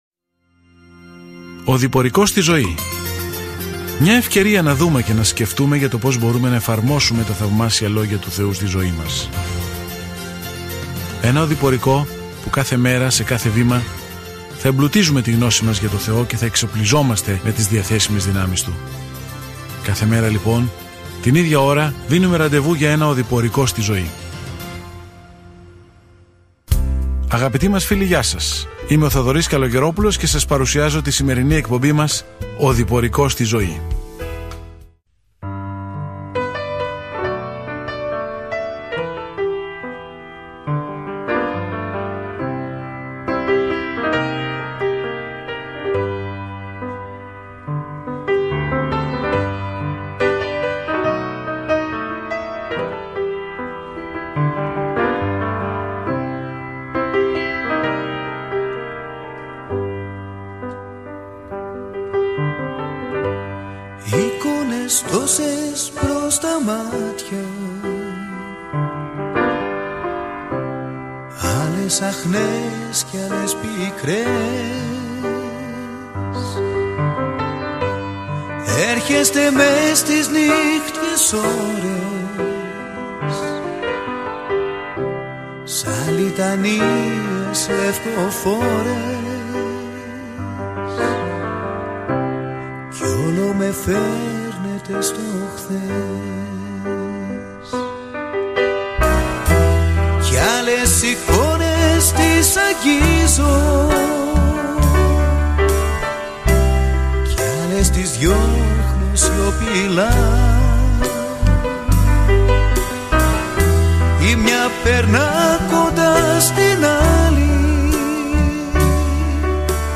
Κείμενο Α΄ ΠΕΤΡΟΥ 1:1-2 Ημέρα 1 Έναρξη αυτού του σχεδίου Ημέρα 3 Σχετικά με αυτό το σχέδιο Εάν υποφέρετε για τον Ιησού, τότε αυτή η πρώτη επιστολή από τον Πέτρο σας ενθαρρύνει ότι ακολουθείτε τα βήματα του Ιησού, ο οποίος υπέφερε πρώτος για εμάς. Καθημερινά ταξιδεύετε στο 1 Πέτρου καθώς ακούτε την ηχητική μελέτη και διαβάζετε επιλεγμένους στίχους από τον λόγο του Θεού.